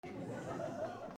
小さな笑い
/ M｜他分類 / L50 ｜ボイス
20人前後 D50